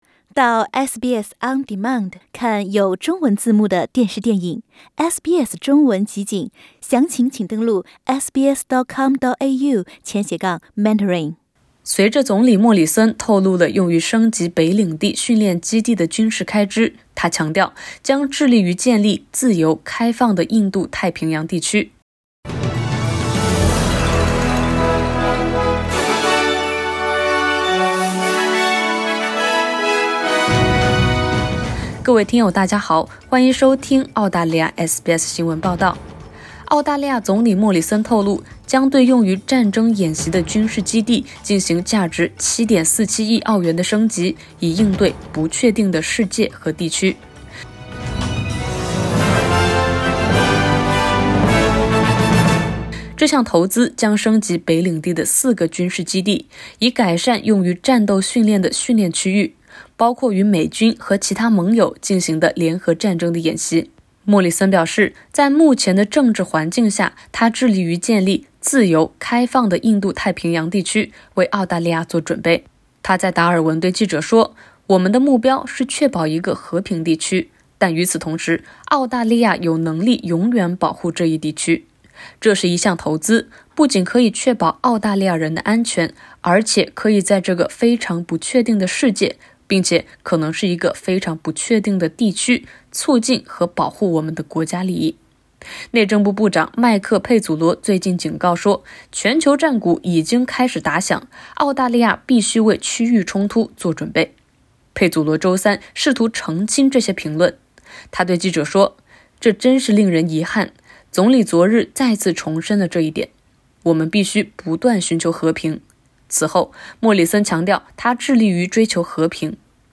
澳大利亚将对用于战争演习的军事基地进行升级，以应对“不确定”的世界和地区。（点击上图收听报道）